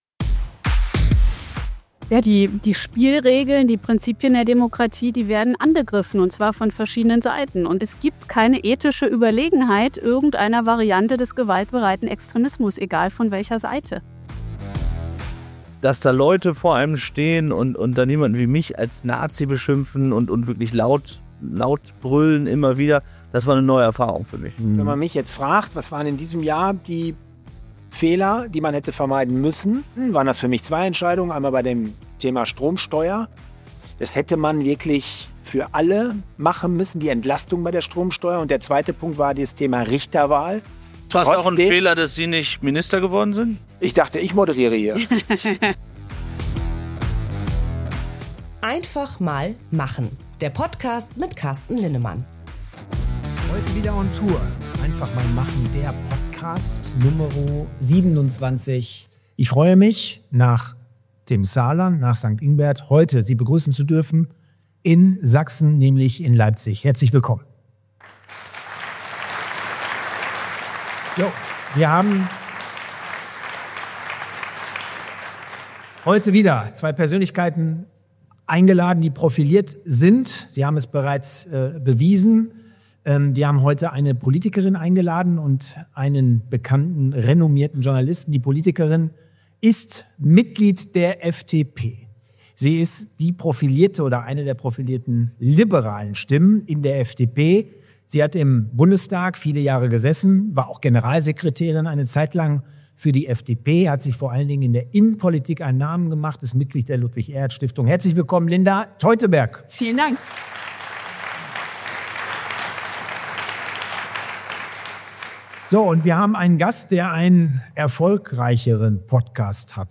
In dieser besonderen Tour-Episode sprechen FDP-Politikerin Linda Teuteberg und Bild-Journalist & Kriegsreporter Paul Ronzheimer über die zunehmende politische Polarisierung, den Umgang mit der AfD, Vertrauensverlust in Institutionen, Medienfehler – und warum Demonstra...
Eine Episode voller Klartext, Einordnung und überraschender Einsichten – mitten aus Leipzig.